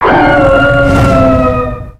Cri de Reshiram dans Pokémon X et Y.